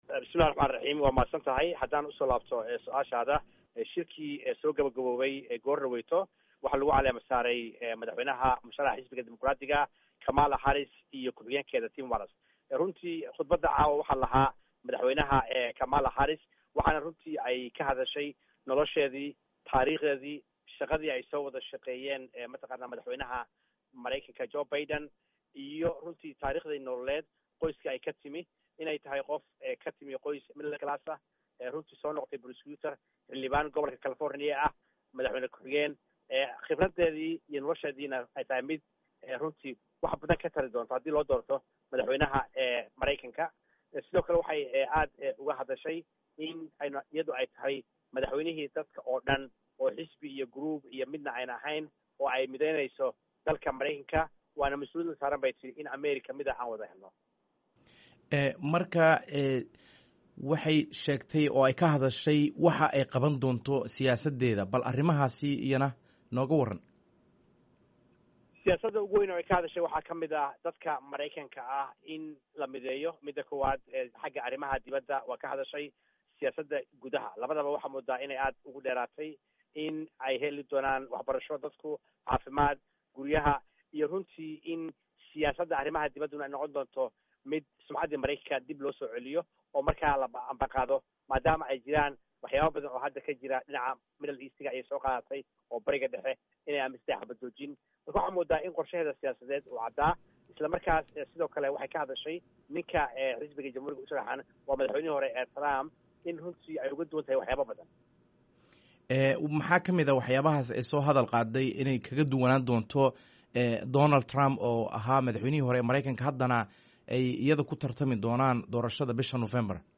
Halkan ka dhageyso mid ka mid ah ergadii ka qeybgashay Shirweynaha Xisbiga Dimuqraadiga